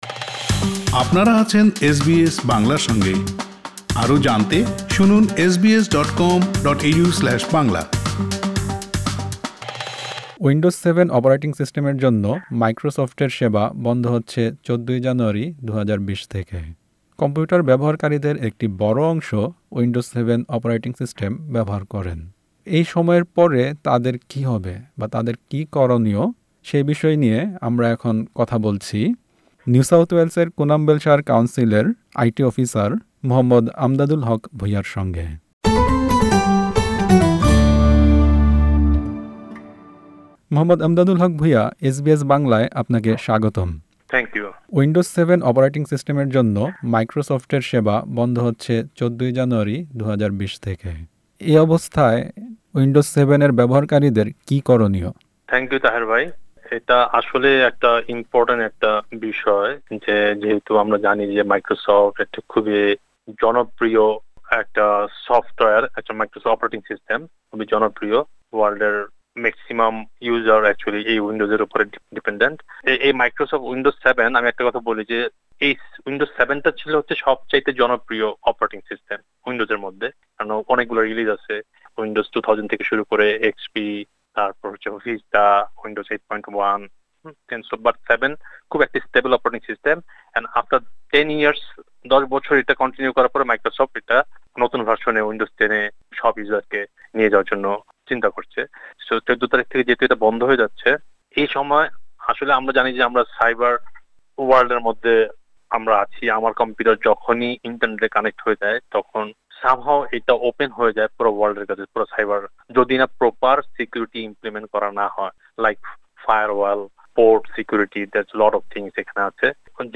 সাক্ষাৎকারটি বাংলায় শুনতে উপরের অডিও প্লেয়ারটিতে ক্লিক করুন।